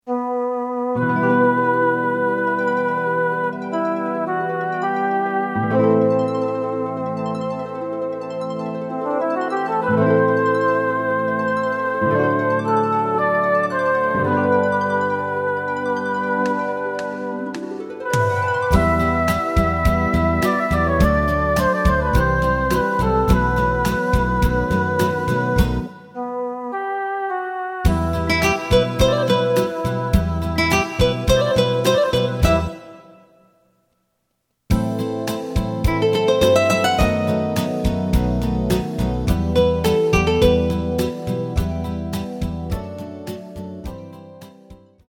エレキバンド用カラオケCD製作・販売
すべての主旋律を１人で演奏するスタイルにアレンジしてみました。
●フルコーラス(カラオケ) 伴奏のみ演奏されます。